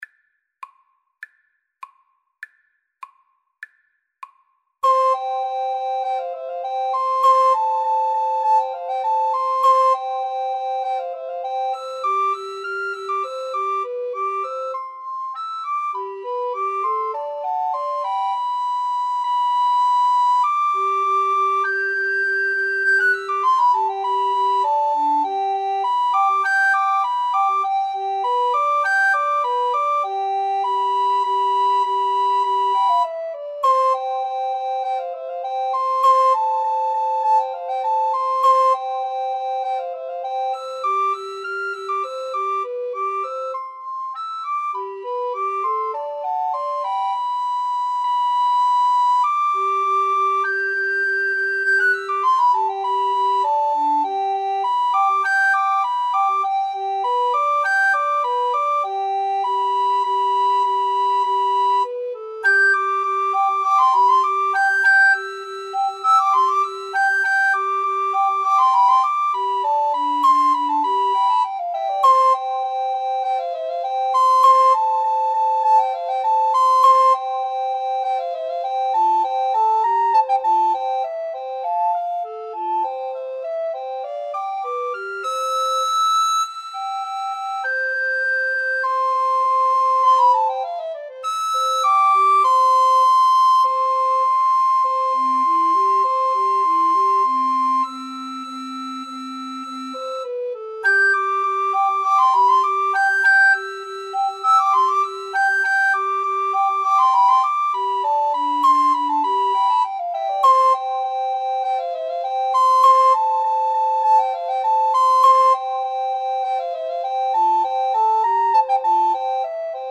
~ = 100 Allegretto
C major (Sounding Pitch) (View more C major Music for Recorder Trio )
Classical (View more Classical Recorder Trio Music)